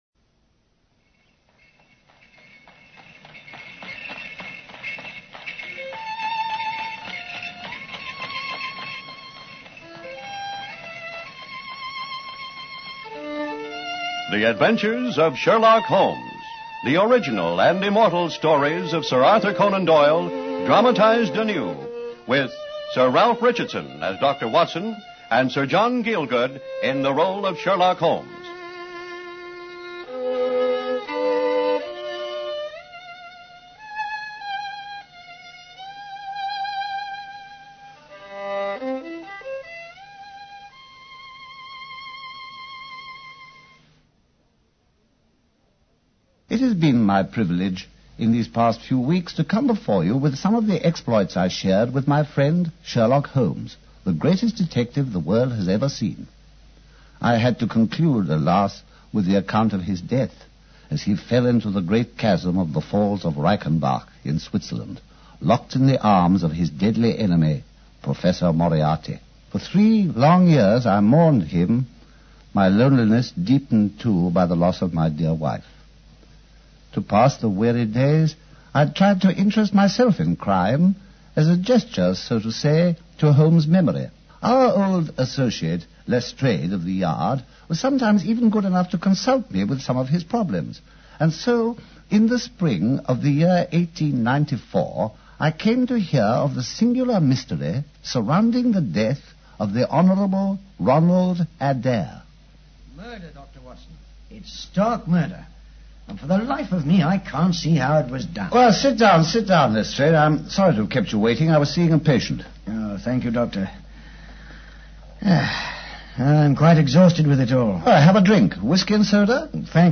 Radio Show Drama with Sherlock Holmes - The Empty House 1954